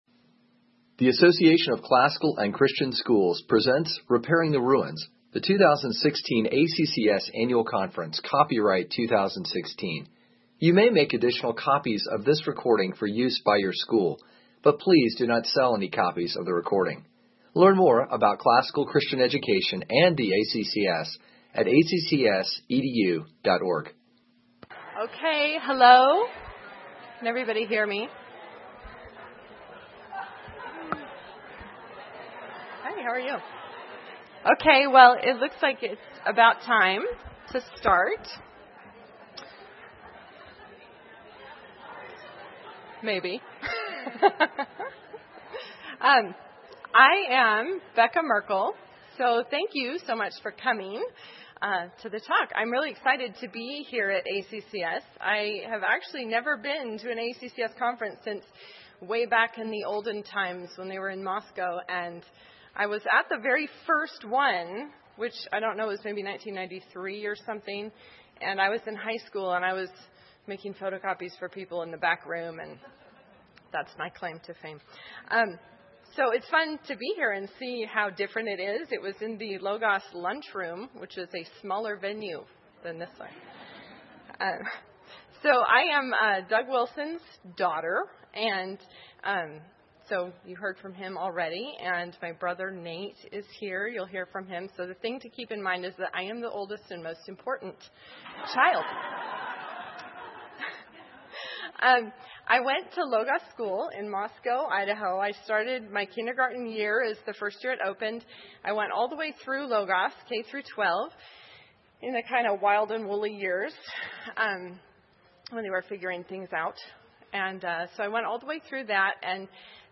2016 Workshop Talk | 0:59:03 | 7-12, Literature
This workshop provides strategies for using debate and discussion in the classroom in a way that teaches the students the differences between an opinion and a fuss, the differences between an opinion and a bald-faced attempt to score points with the teacher, and the differences between a well-informed opinion and a badly informed opinion. Speaker Additional Materials The Association of Classical & Christian Schools presents Repairing the Ruins, the ACCS annual conference, copyright ACCS.